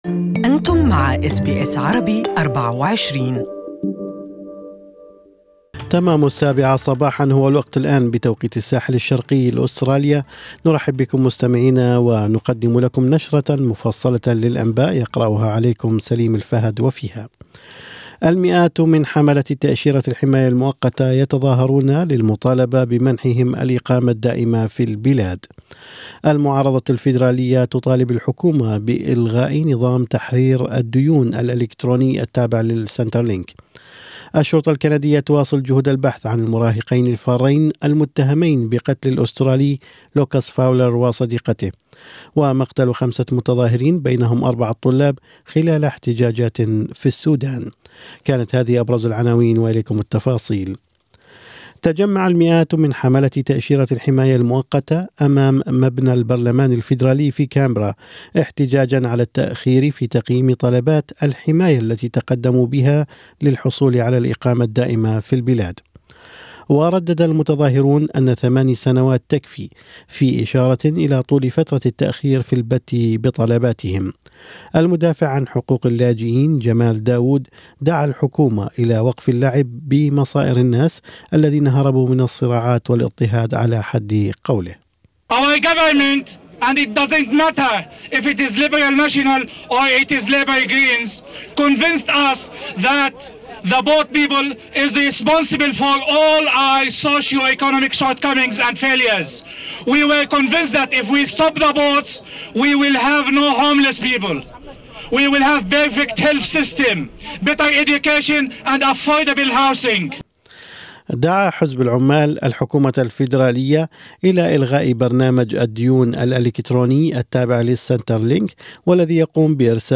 Morning News: calls to scrap Centrlink's robodebt program